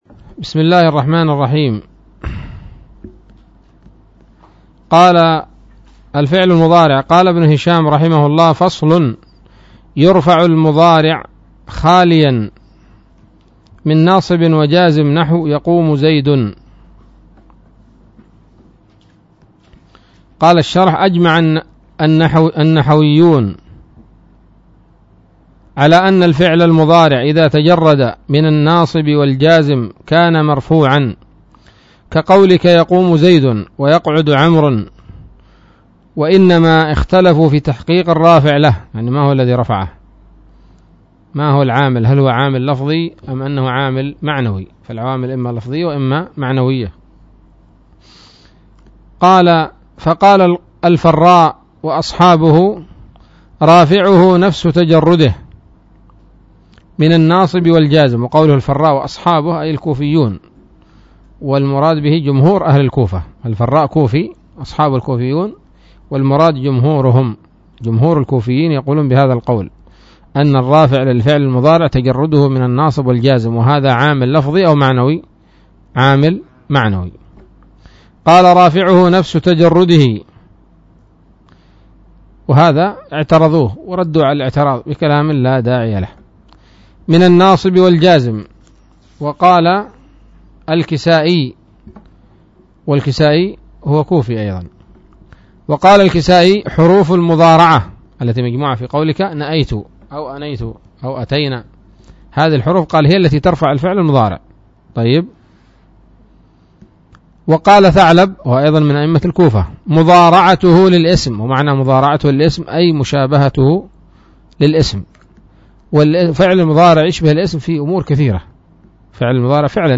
الدرس السابع والعشرون من شرح قطر الندى وبل الصدى